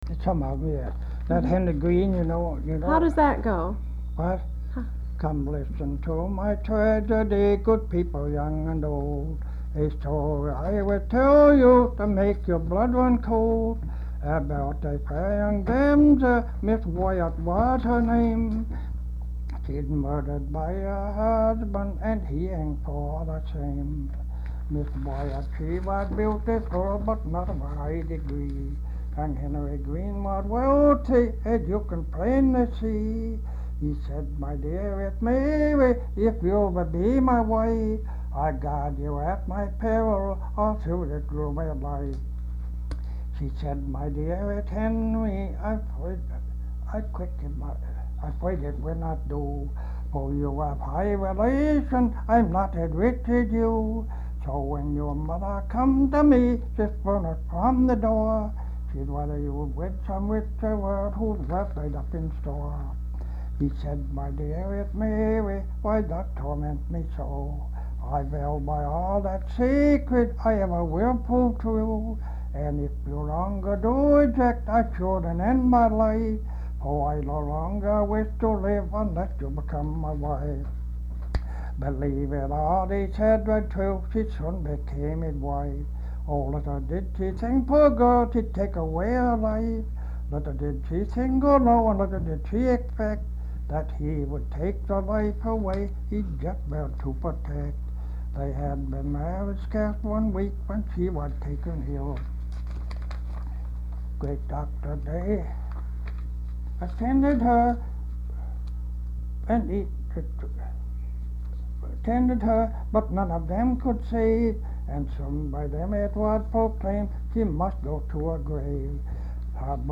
Folk songs, English--Vermont
sound tape reel (analog)
Marlboro, Vermont